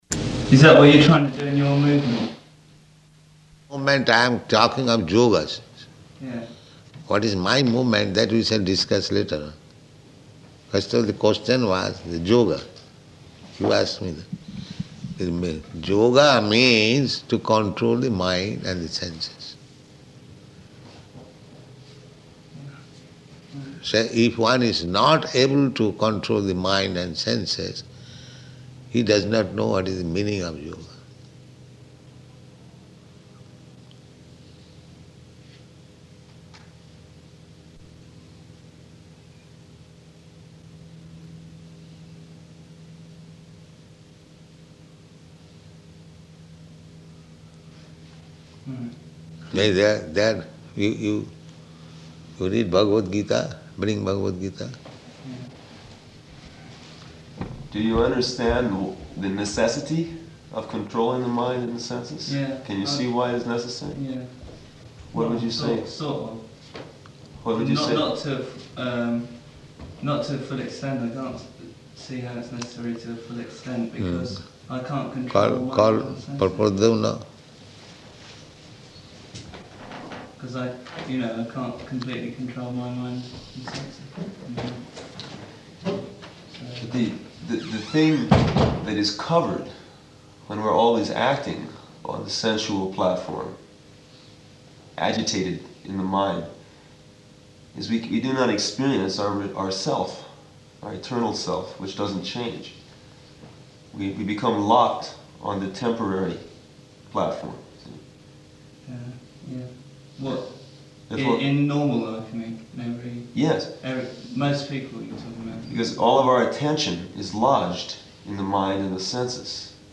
Room Conversation With Three College Students
Room Conversation With Three College Students --:-- --:-- Type: Conversation Dated: July 15th 1973 Location: London Audio file: 730715R1.LON.mp3 Student (1): Is that what you're trying to do in your movement?